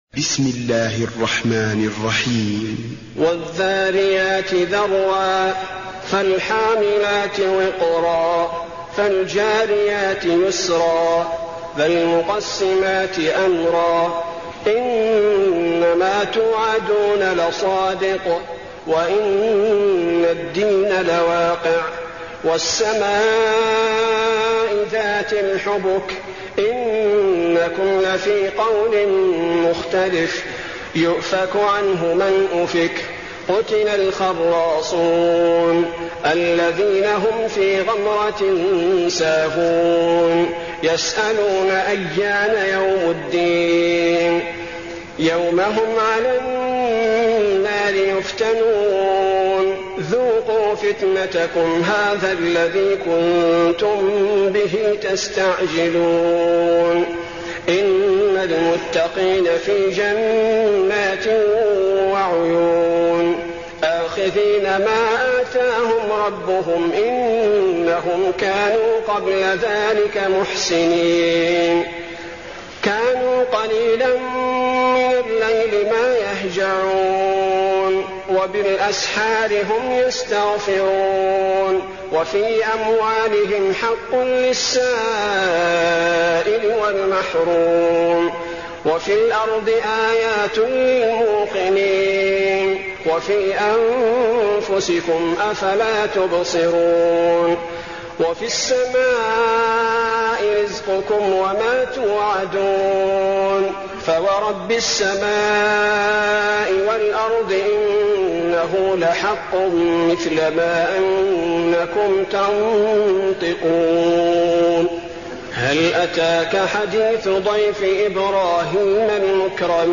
المكان: المسجد النبوي الذاريات